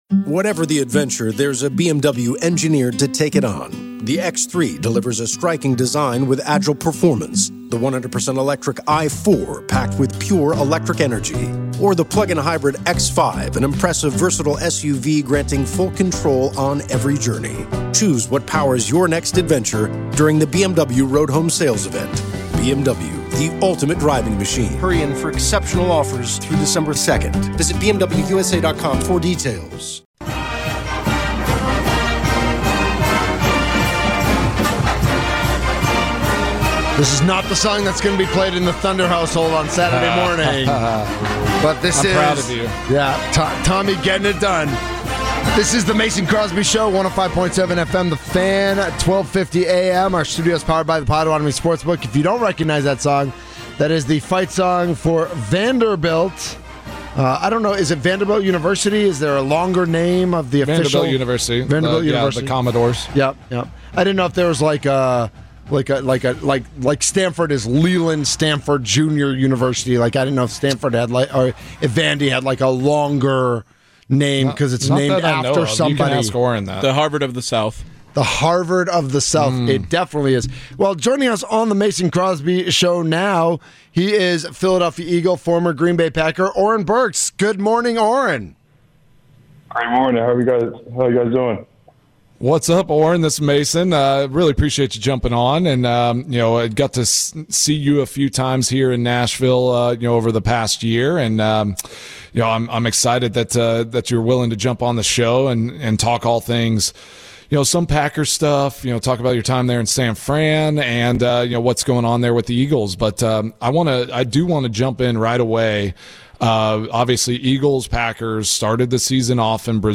Also hear players coaches and our Football insiders.